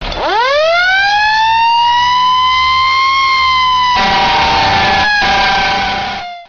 siren.mp3